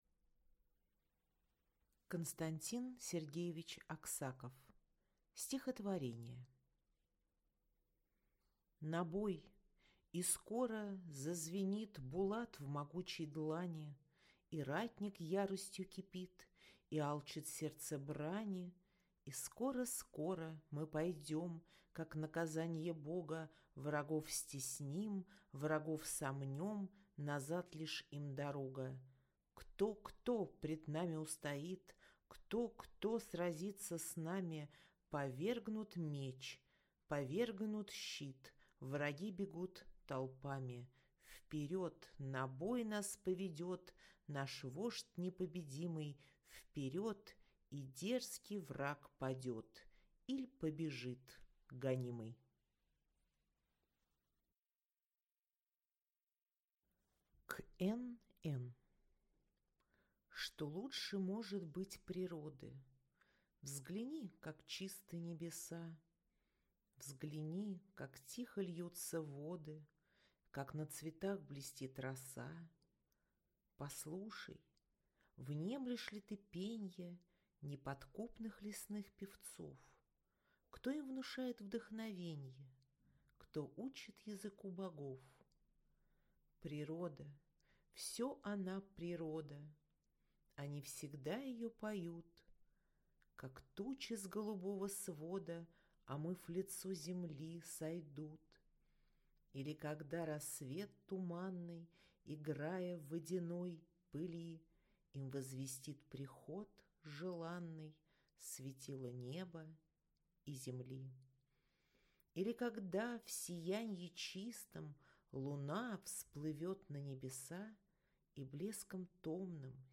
Аудиокнига Стихотворения | Библиотека аудиокниг